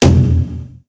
Taiko no Tatsujin 2020 Version - Common Sound Effects